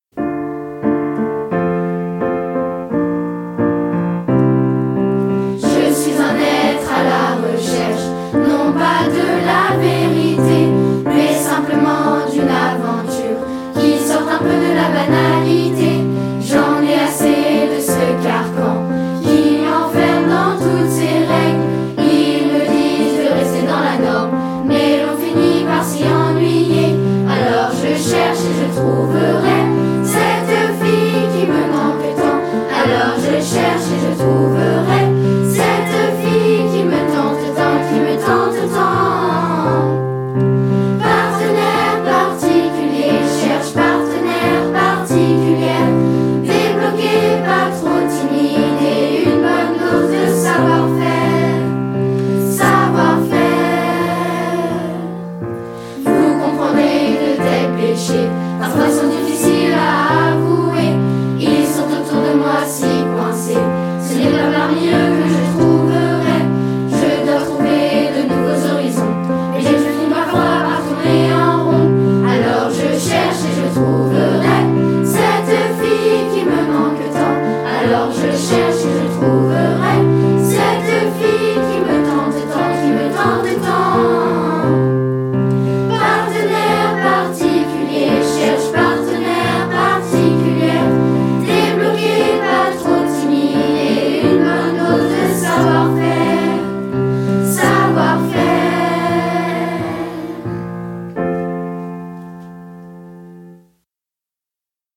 En effet, les élèves de la chorale pendant cette semaine culturelle ont enregistré 7 chansons apprises cette année, afin de réaliser un CD.